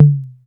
Machine_Tom.wav